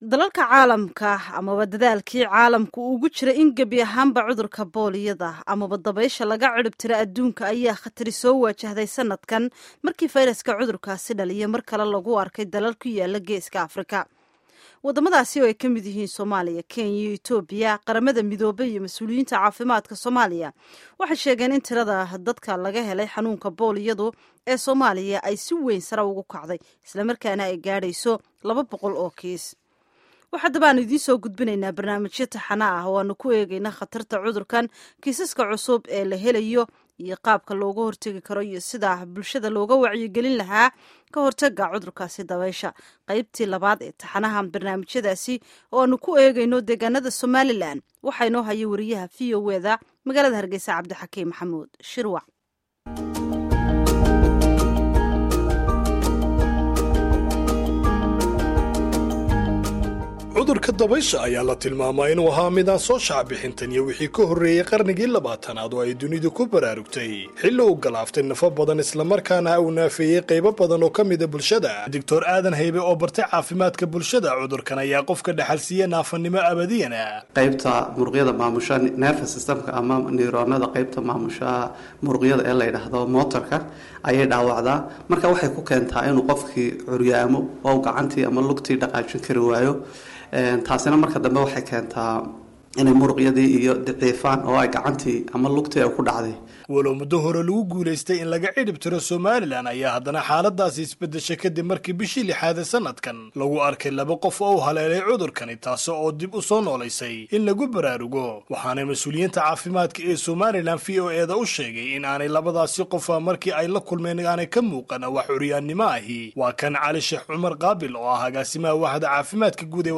Embed share Warbixinta Hargeysa by VOA Embed share The code has been copied to your clipboard.